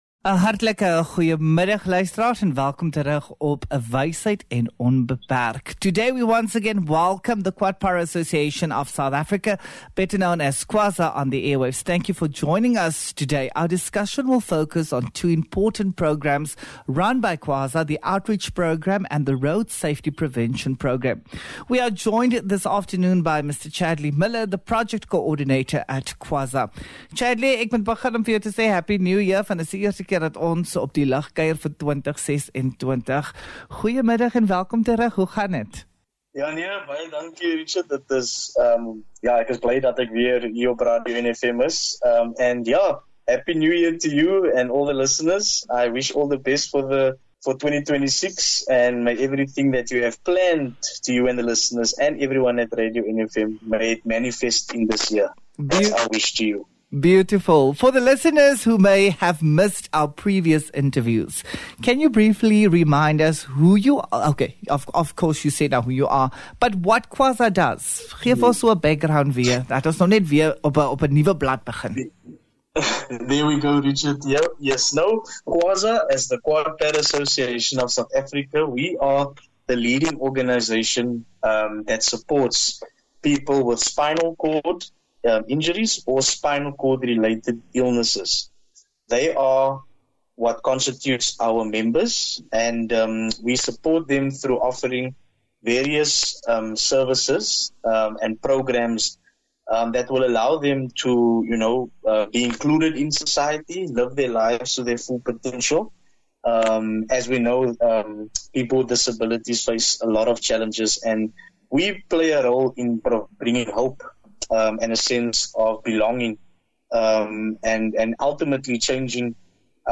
Attachments DISABLED DESK INTERVIEW ROAD SAFETY 28 JAN 26.mp3 (16 MB)